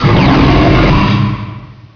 pokeemerald / sound / direct_sound_samples / cries / kyurem_black.aif